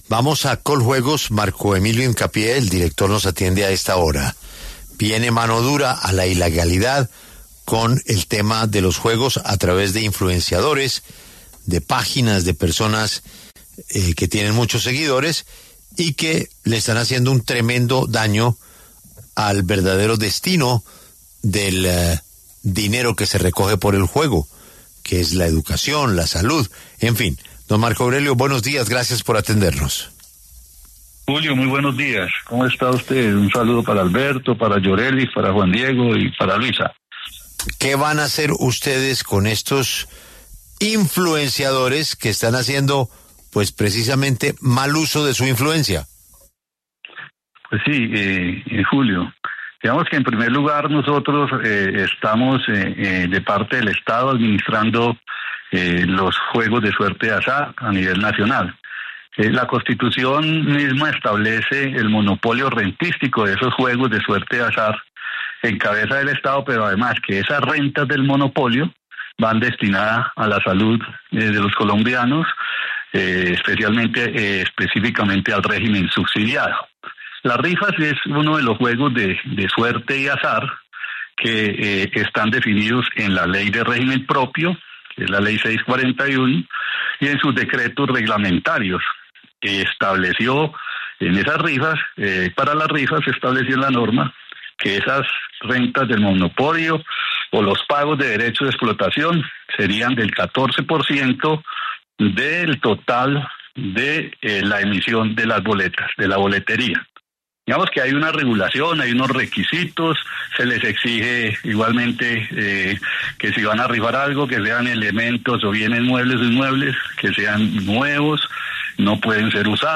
El presidente de Coljuegos, Marco Emilio Hincapié, habló en La W sobre la solicitud a Facebook y al Centro Cibernético Policial el bloqueo de 289 perfiles de redes sociales como Facebook e Instagram que han realizado rifas en casas de apuestas no autorizadas.